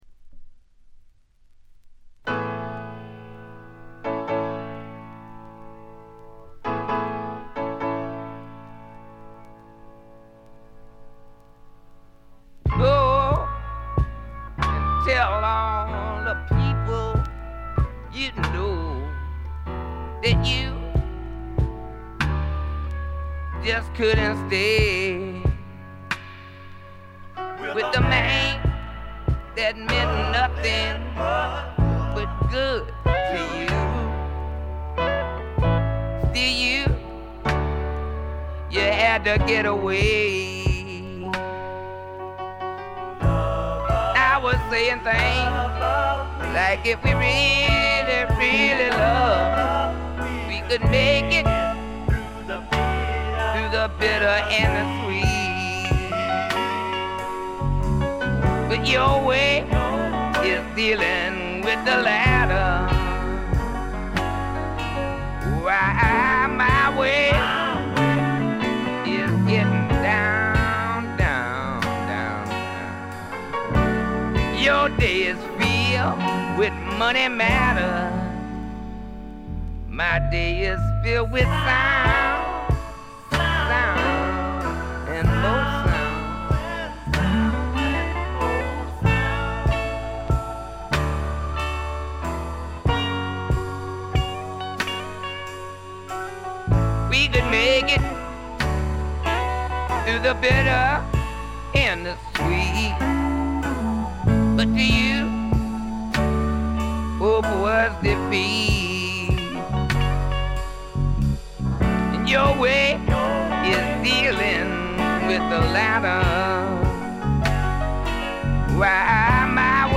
部分試聴ですが、静音部での軽微なチリプチ程度。
試聴曲は現品からの取り込み音源です。